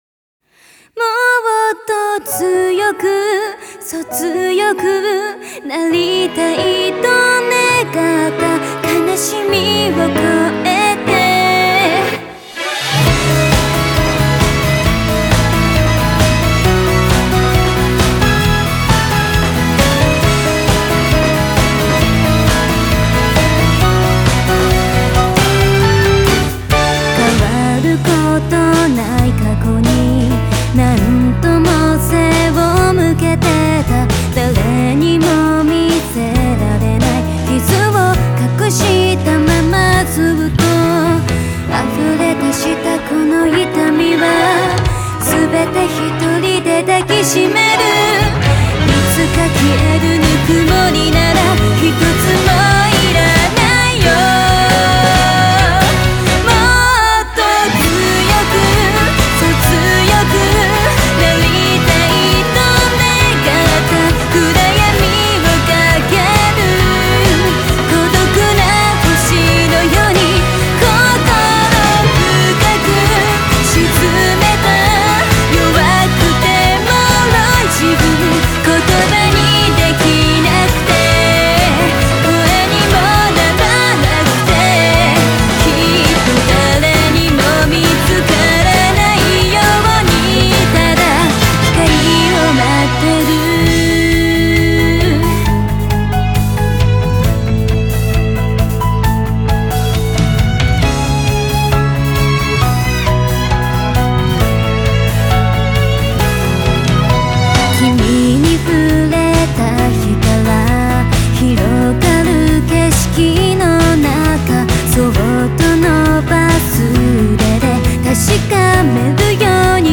Country: Japan, Genre: J-Pop